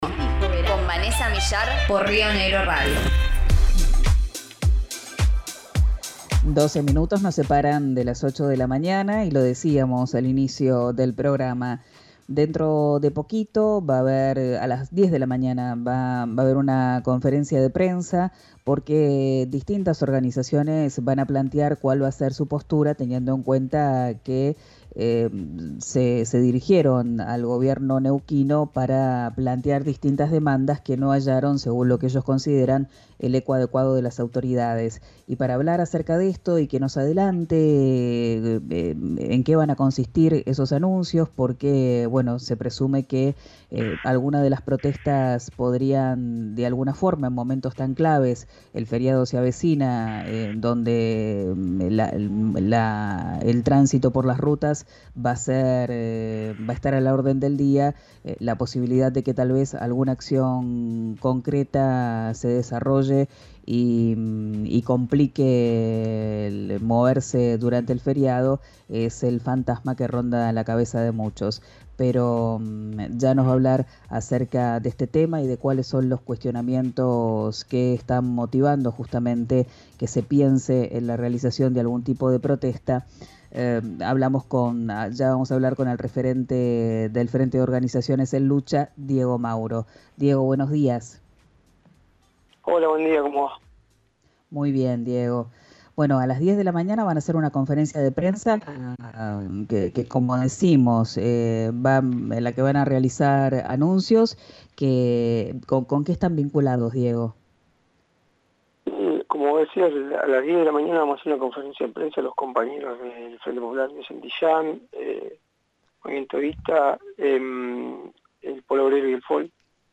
En comunicación con RÍO NEGRO RADIO